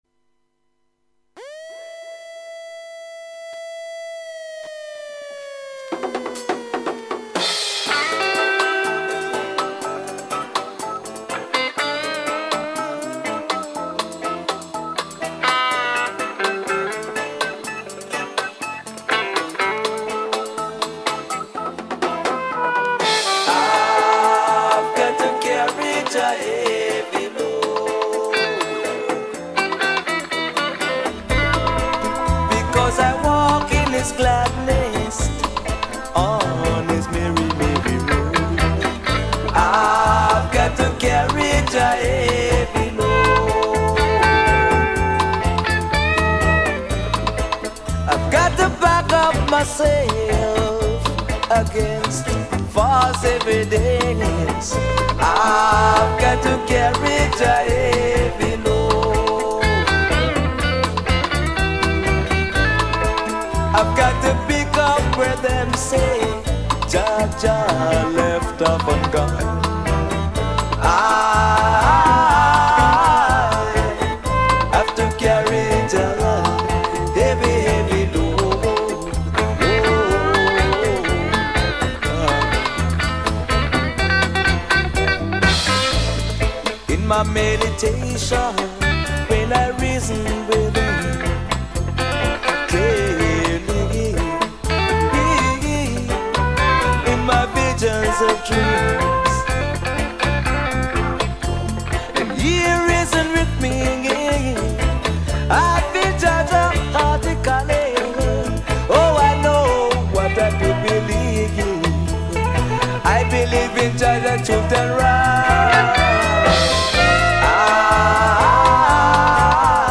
Mix 100% vynile